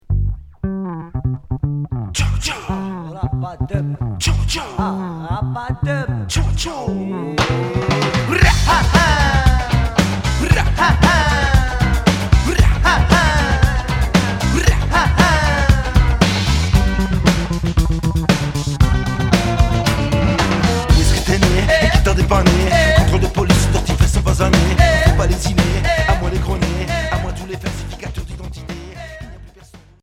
Rock ska rap Deuxième 45t retour à l'accueil